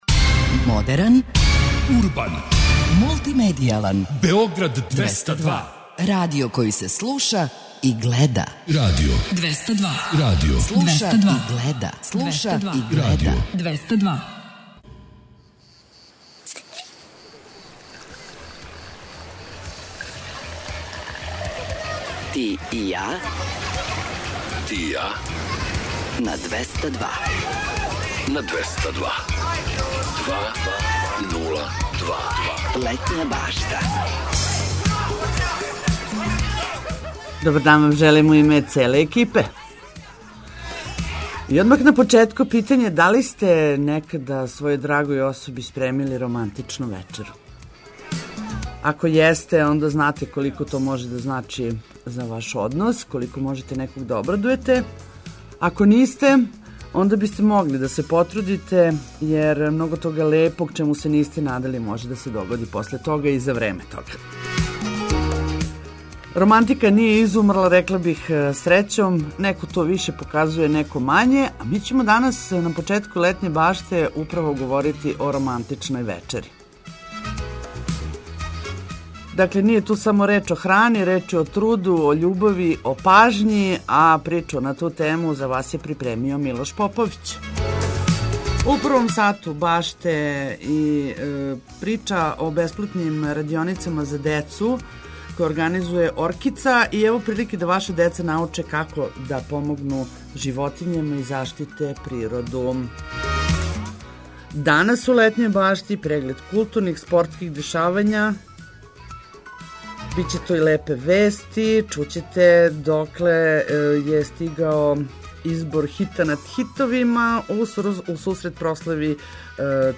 Шта је потребно за „романтични сто за двоје", питали смо грађане различитих година, а открили су и како се они потруде да нахране свог пара.
Причамо о спортским актуелностима, препоручујемо занимљиве догађаје широм Србије, а зачин дану биће и лепе вести и музика.